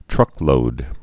(trŭklōd)